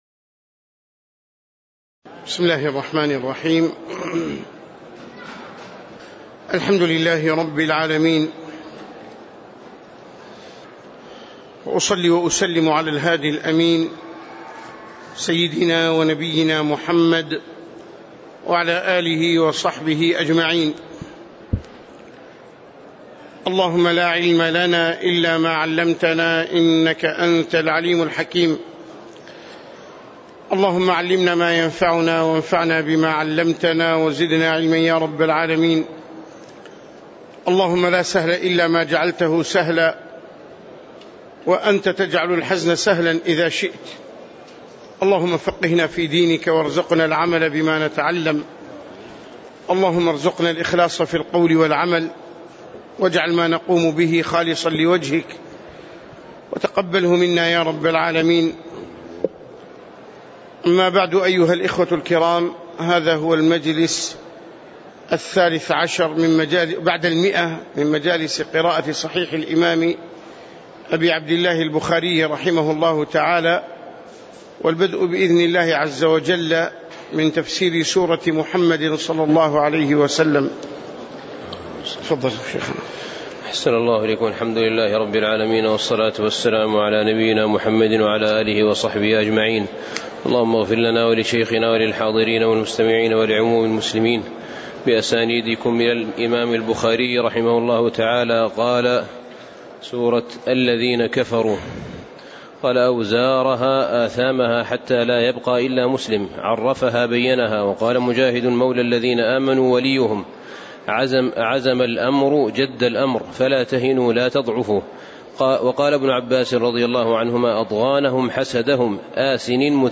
تاريخ النشر ٧ شعبان ١٤٣٨ هـ المكان: المسجد النبوي الشيخ